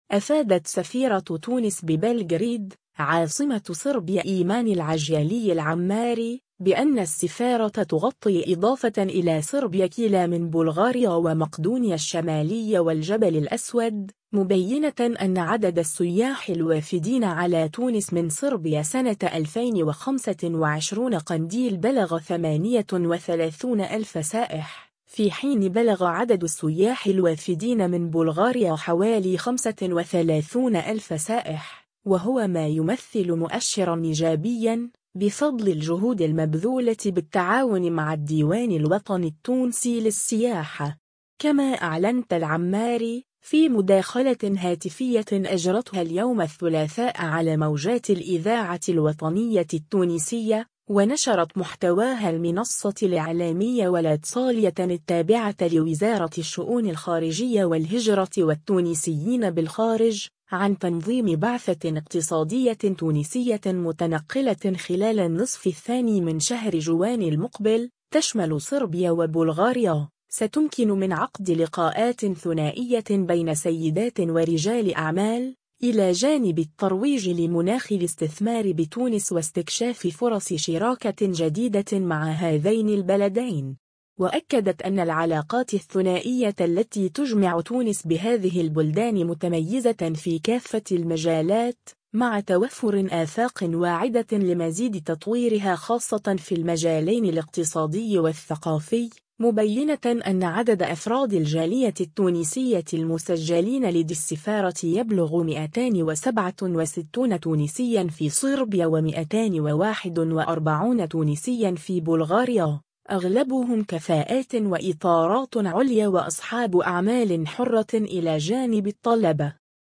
كما أعلنت العماري، في مداخلة هاتفية أجرتها اليوم الثلاثاء على موجات الإذاعة الوطنية التونسية، ونشرت محتواها المنصة الاعلامية والاتصالية التابعة لوزارة الشؤون الخارجية والهجرة والتونسيين بالخارج، عن تنظيم بعثة اقتصادية تونسية متنقلة خلال النصف الثاني من شهر جوان المقبل، تشمل صربيا وبلغاريا، ستمكن من عقد لقاءات ثنائية بين سيدات ورجال أعمال، إلى جانب الترويج لمناخ الاستثمار بتونس واستكشاف فرص شراكة جديدة مع هذين البلدين.